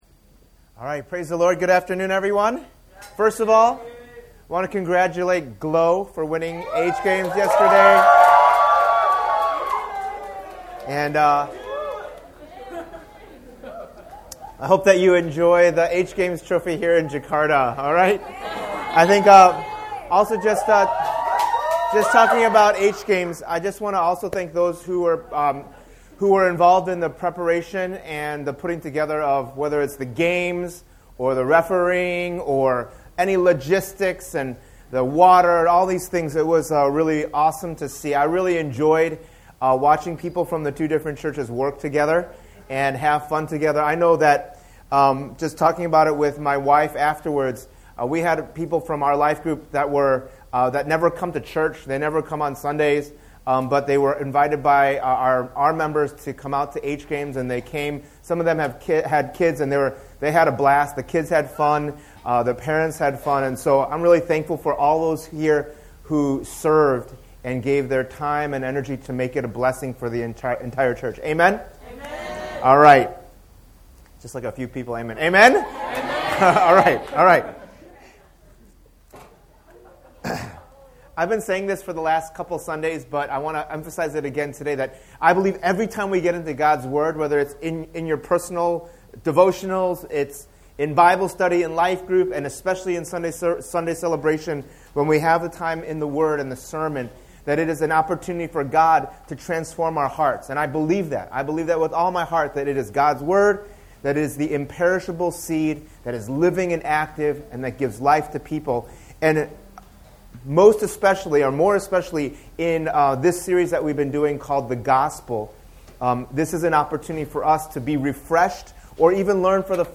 So as a church, more than a splattering of doctrines, more than a variety of programs, in order to have a sound framework for all of life, we need to be centered on the Gospel. In this three-part sermon series, we’ll see how the Gospel fits into God’s redemptive history in Part 1: The Full Picture, and then what the Gospel does in us in Part 2: A New Heart, and finally how the Gospel works through us in Part 3: Good Works.